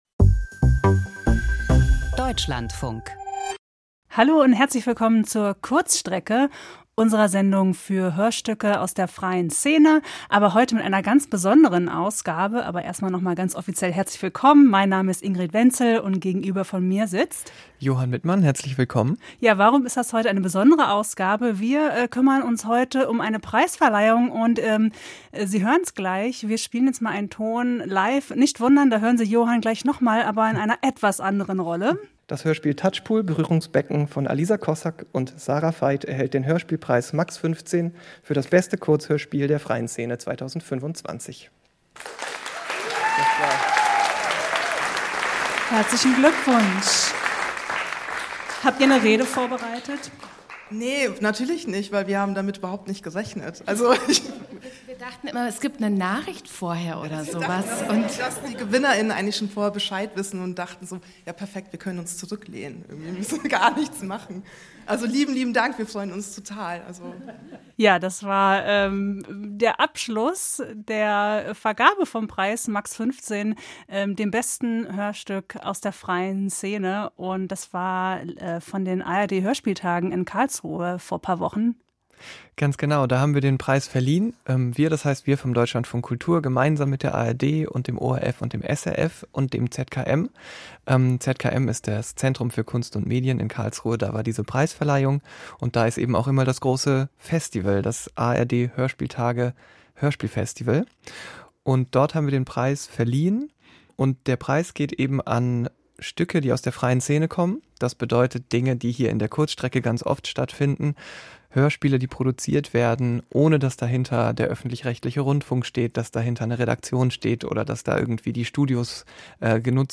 Innovative und zeitgemäße Audioproduktionen aus den Genres Feature, Hörspiel und Klangkunst.